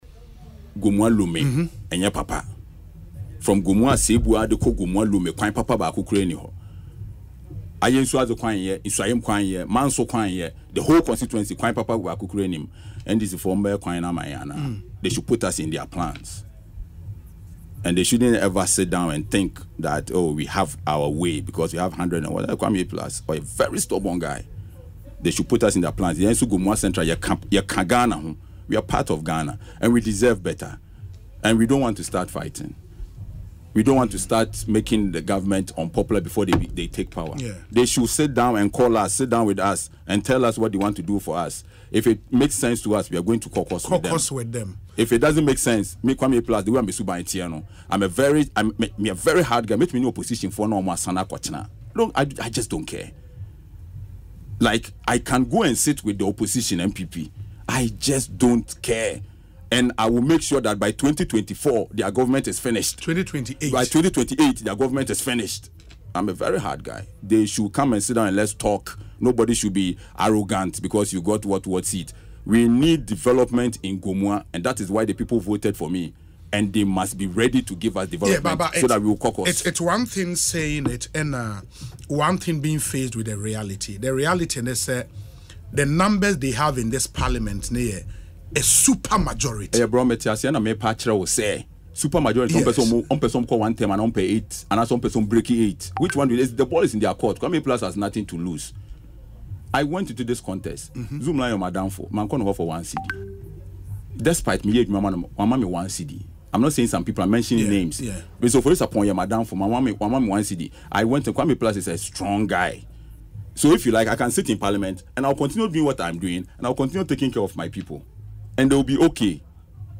In an interview on Asempa FM’s Ekosii Sen programme Wednesday, he cautioned the NDC not to allow their super majority to get into their heads.